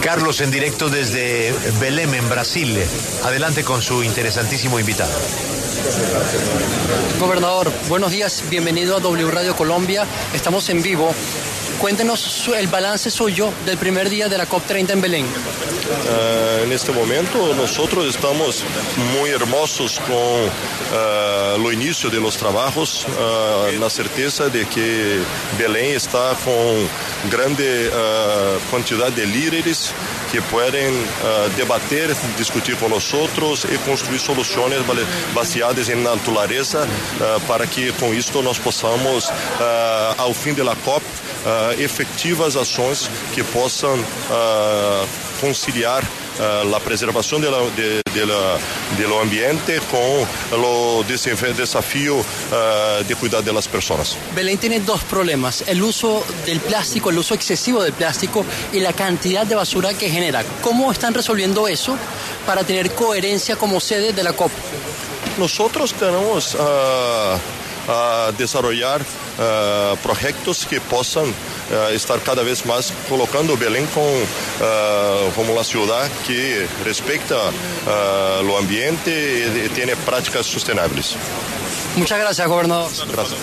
Helder Zahluth Barbalho, exsecretario Nacional de Puertos, exministro de Integración Nacional y actual gobernador del estado de Pará en Brasil, habló con La W a propósito del desarrollo de la COP30 que se desarrolla en Brasil.